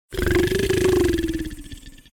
beast_calm_growl.ogg